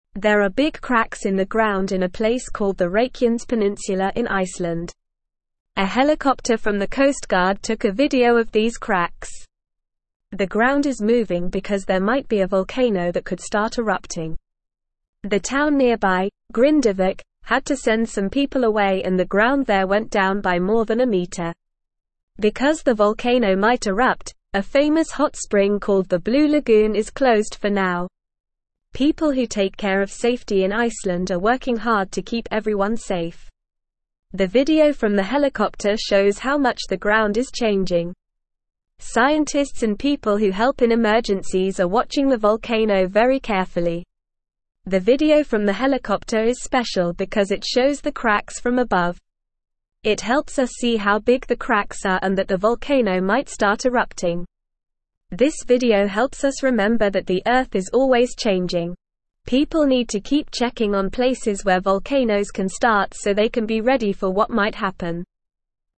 Normal
English-Newsroom-Lower-Intermediate-NORMAL-Reading-Cracks-in-Ground-Volcano-Waking-Up-in-Iceland.mp3